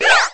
girl_toss_phone.wav